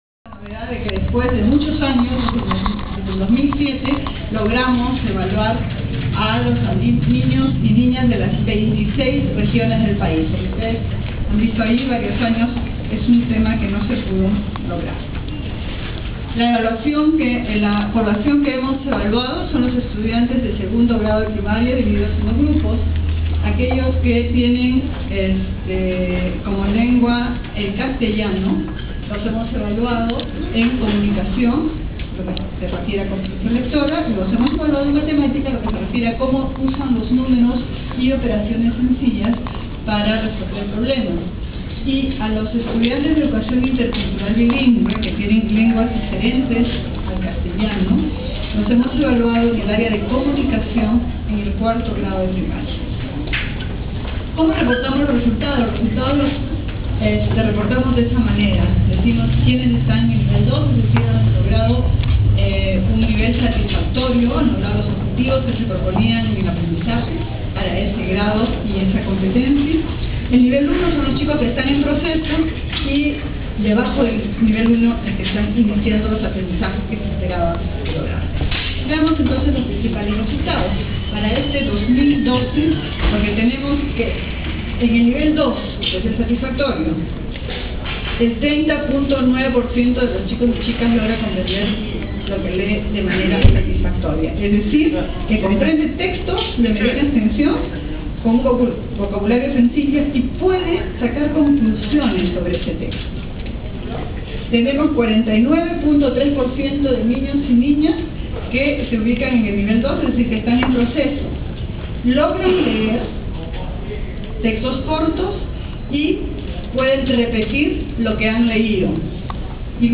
Audio de la presentación de resultados ECE 2012, a cargo de la Ministra Patricia Salas